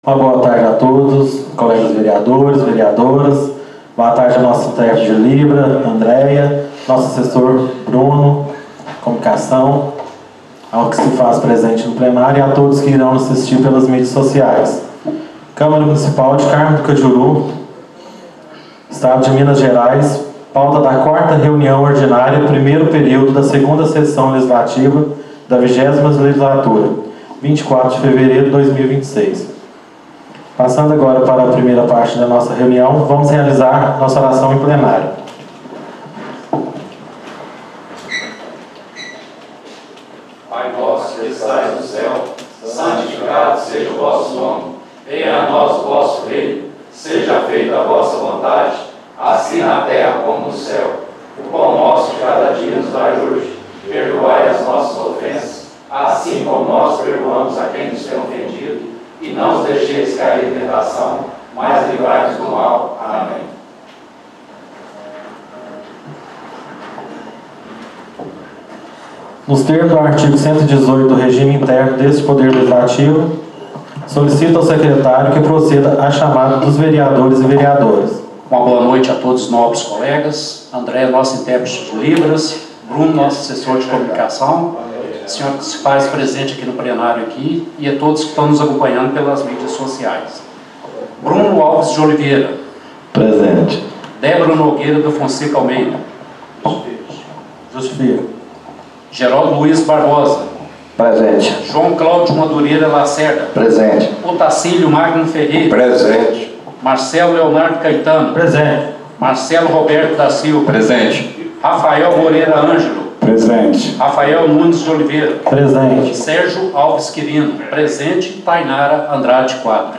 Áudio da 04 Reunião Ordinária 2026 - Câmara Municipal de Carmo do Cajuru | Gestão 2026-2027